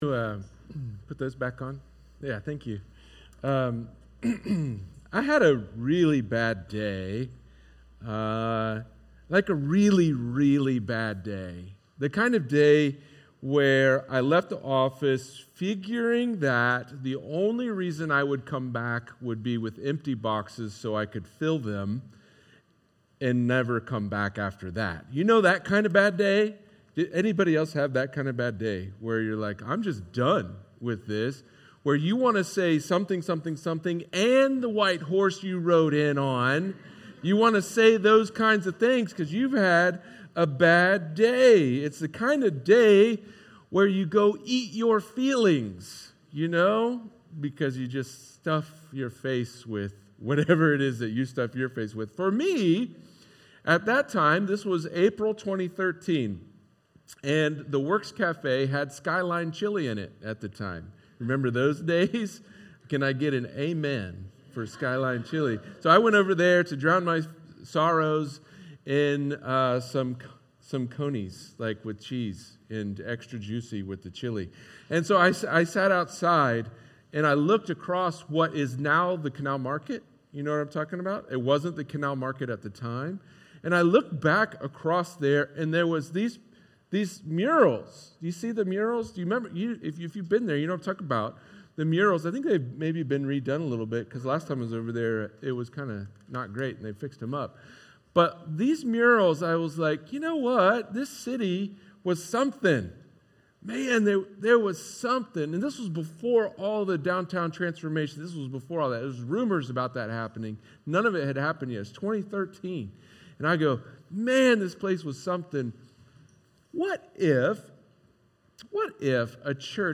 He Turned His Face Toward Jerusalem – Palm Sunday – 5 Year Anniversary Celebration